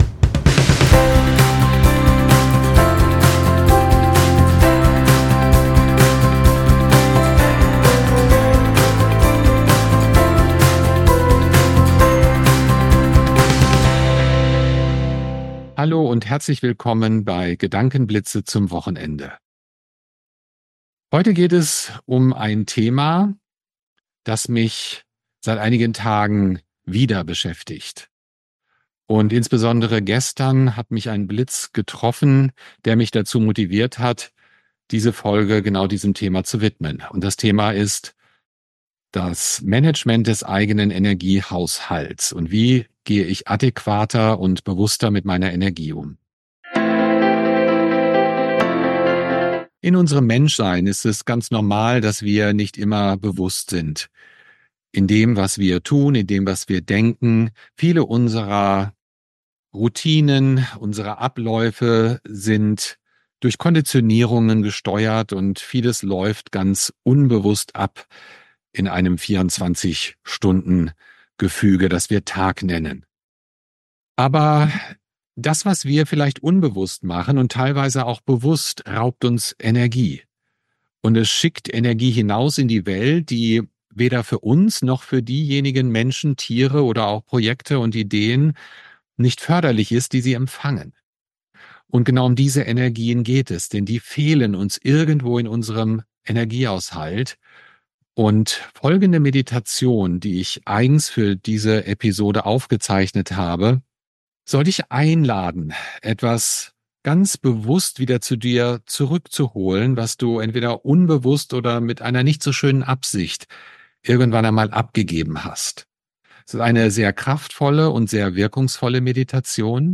In dieser Folge von Gedankenblitzen lade ich dich zu einer klärenden Meditation ein, die dabei unterstützt, Energie, die in die Welt projiziert wurde, wieder zu integrieren.